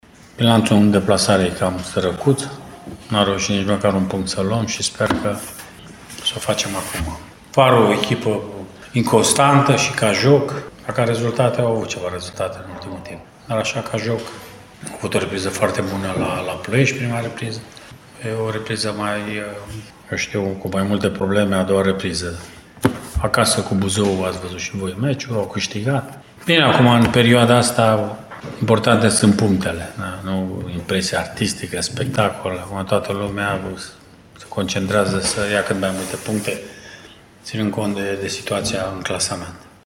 Antrenorul principal Mircea Rednic recunoaște că bilanțul recent al meciurilor externe este unul ”subțire”, astfel încât tactica e stabilită pentru abordarea la rezultat a acestei ultime deplasări din campionat pentru Bătrâna Doamnă: